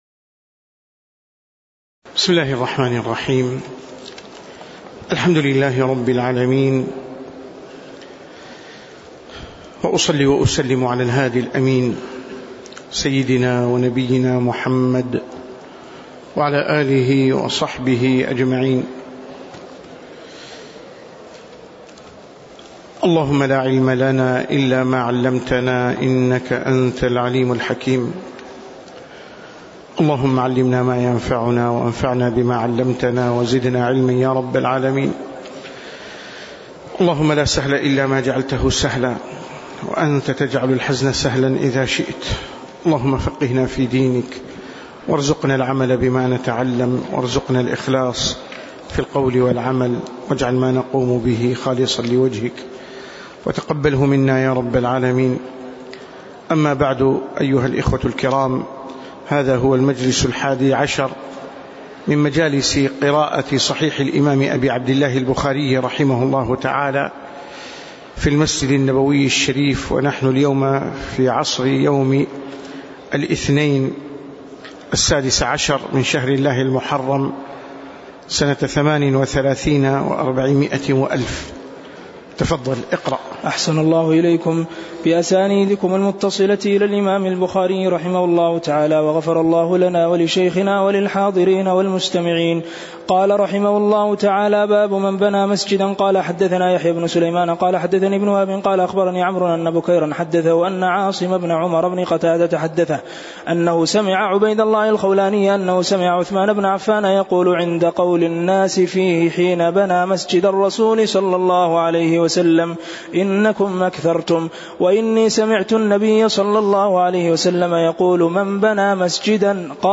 تاريخ النشر ١٦ محرم ١٤٣٨ هـ المكان: المسجد النبوي الشيخ